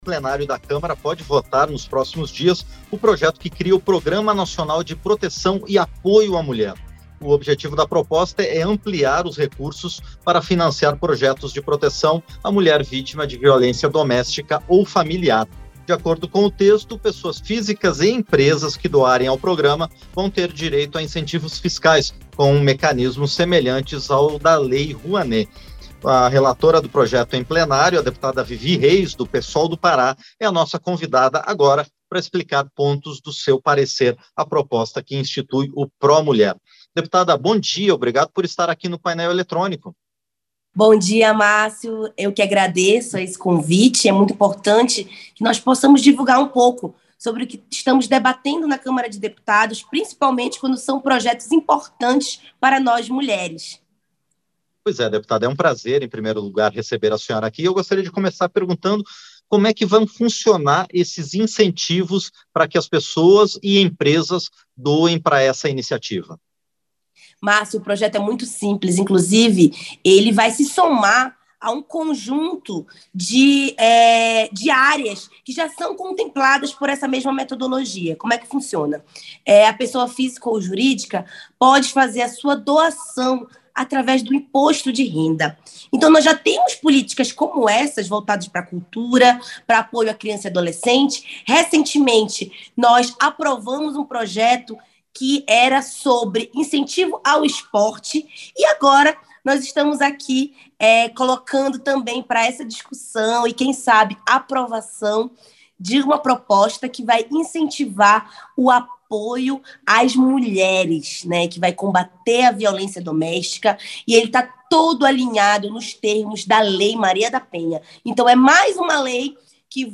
Entrevista - Dep. Vivi Reis (Psol-PA)